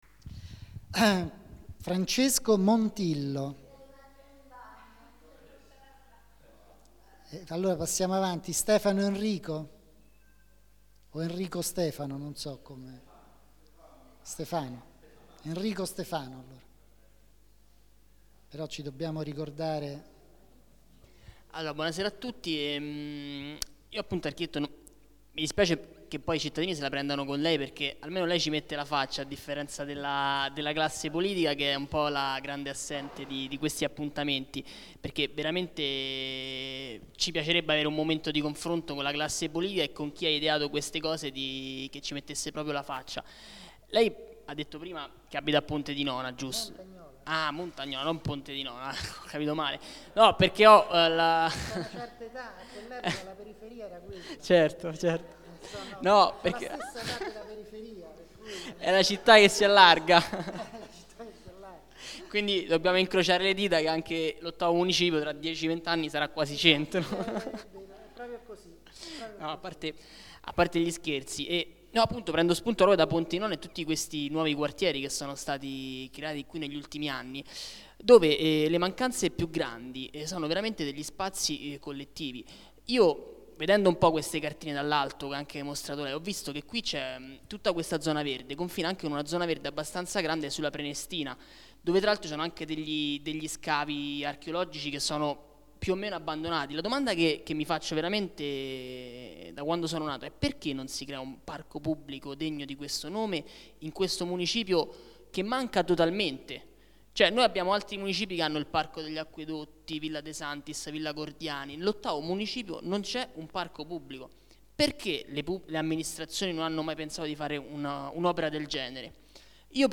Assemblea Partecipativa TBMRegistrazione integrale dell'incontro svoltosi il 13 settembre 2012 presso la sala consiliare del Municipio VIII in Via D. Cambellotti, 11.